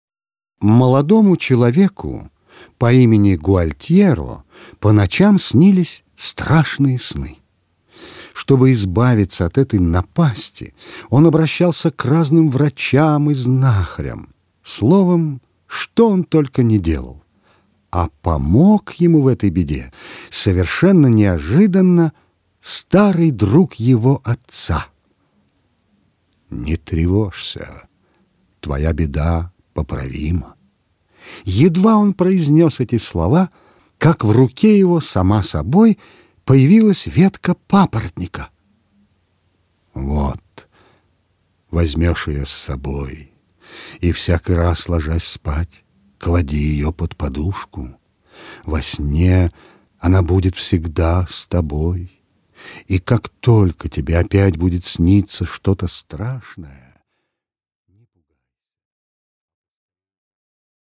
Аудиокнига Сны Гуальтьеро | Библиотека аудиокниг